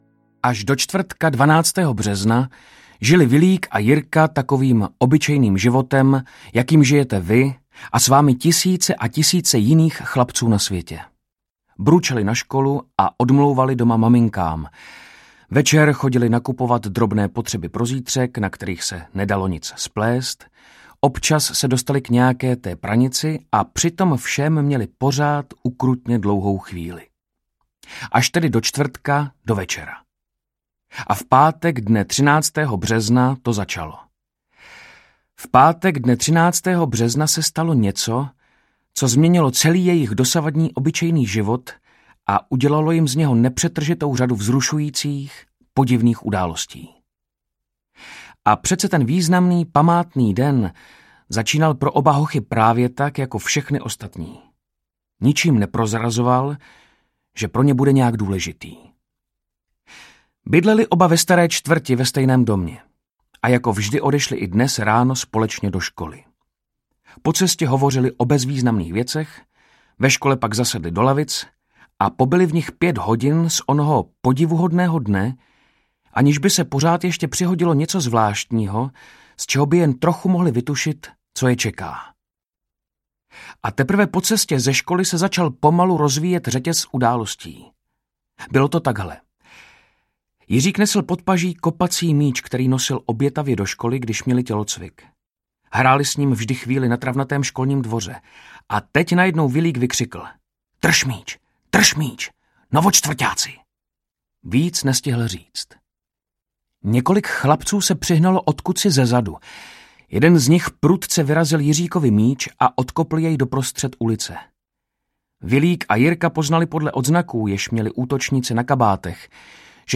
Hoši od Bobří řeky audiokniha